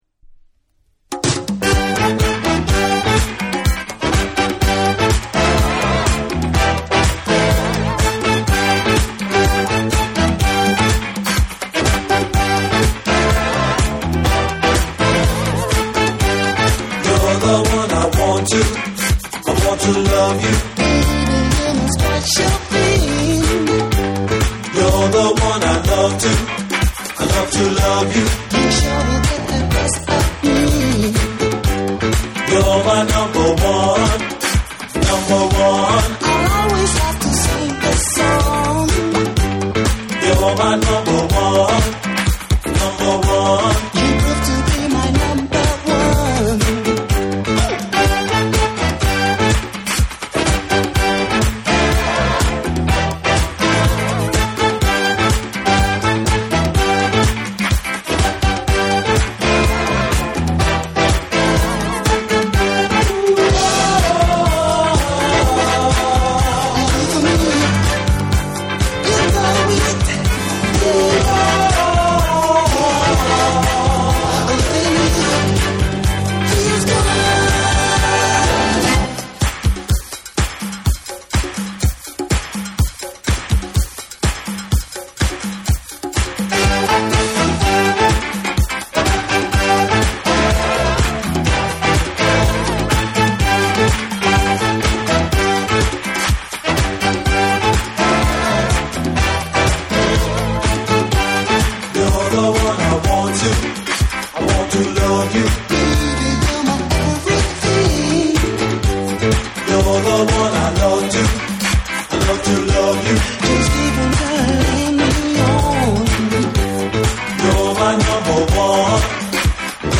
DANCE CLASSICS / DISCO / RE-EDIT / MASH UP